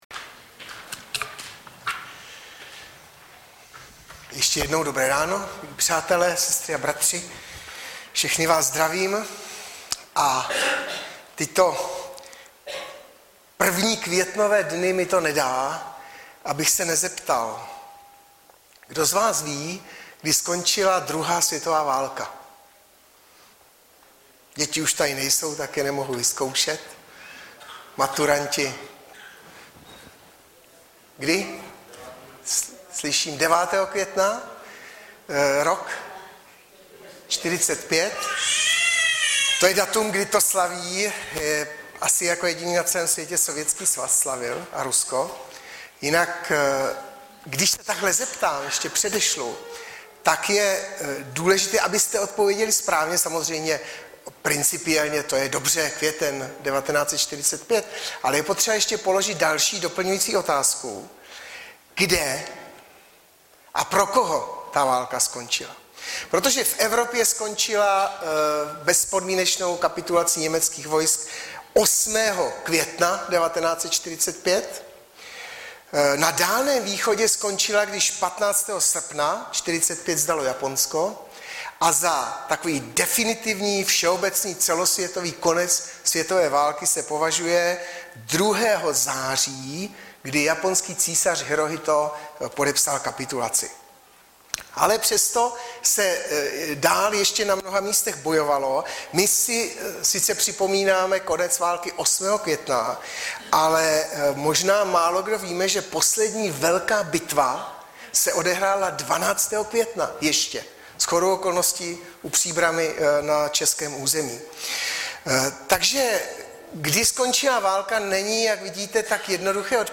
Baptisté v Litoměřicích
Kázání